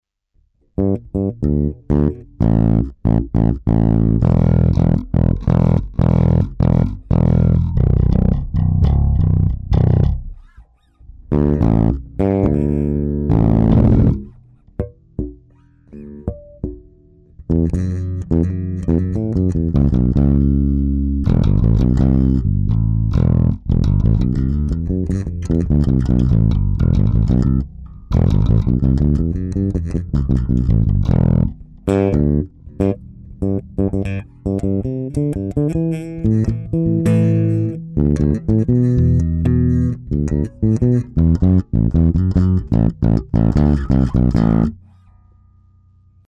Chlapi nějak mě začala zlobit basa, resp. asi elektronika, poslechněte nahrávku, strašně jakoby brumí a kreslí, v aparátu to pak dělá neplechu... stejně už ted ale bude aspon o duvod víc vyměnit za aguilar obp-3, kterou tam chci už dlouho dát...